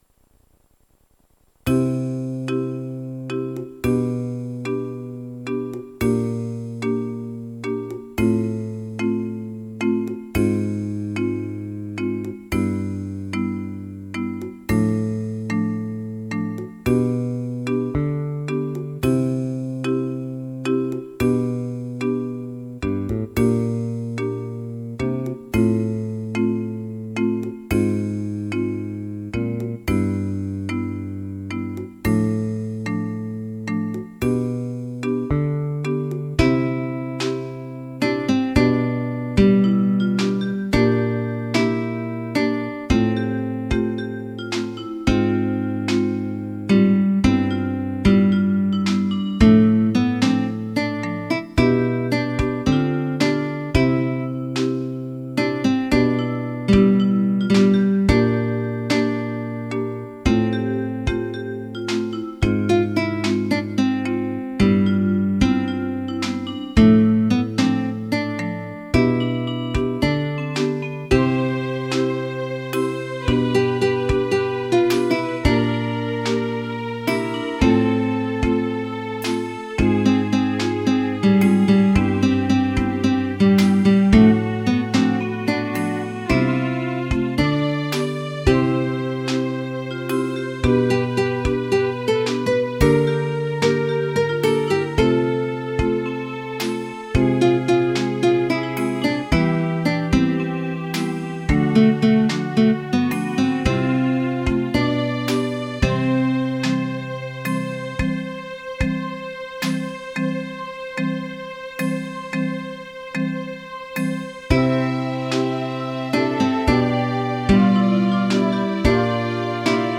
癒し系ＢＧＭ第１弾です。
何箇所か不協和音が残ってます・・・。